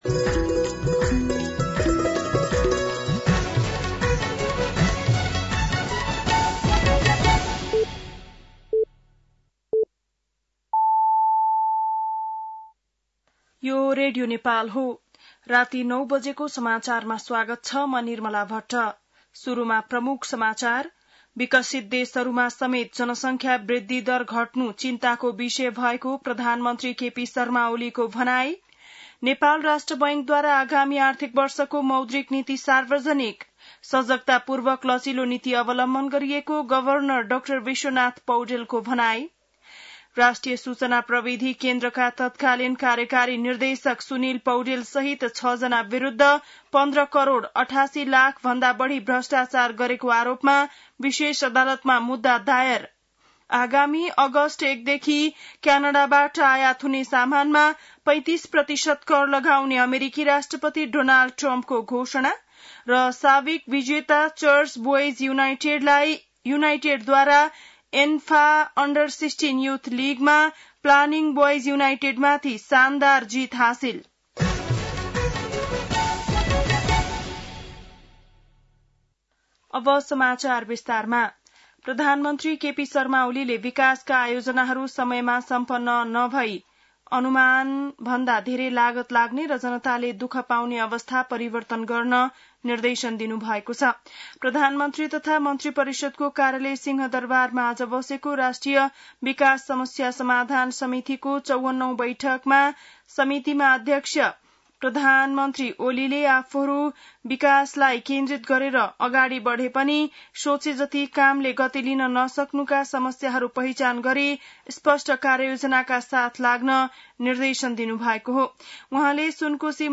बेलुकी ९ बजेको नेपाली समाचार : २७ असार , २०८२
9-PM-Nepali-NEWS-3-27.mp3